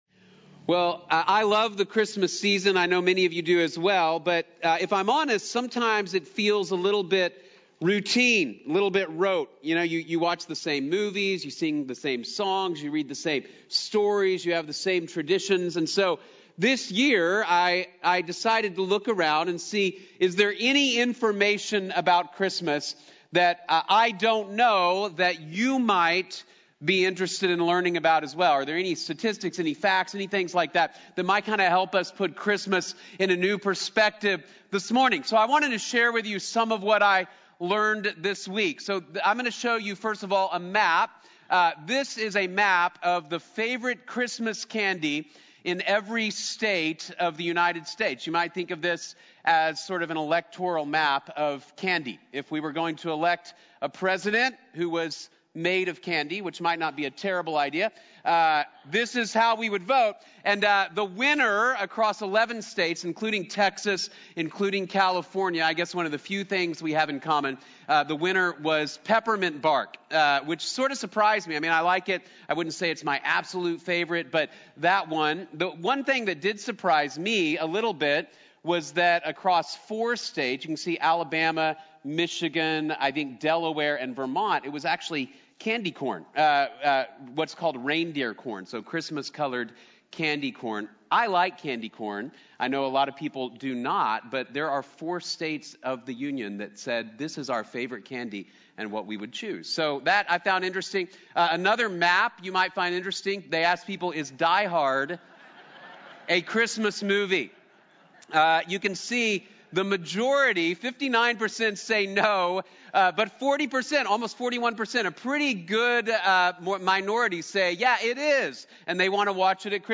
The Wonder of Christmas | Sermon | Grace Bible Church